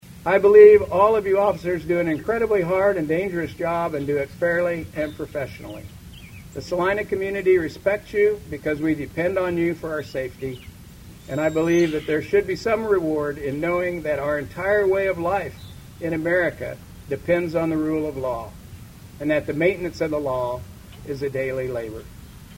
A Peace Officer Memorial Day event was held at Jerry Ivey Park.
Salina Mayor Karl Ryan, who helped with the funeral services of the last police officer killed in Salina, was among those who spoke.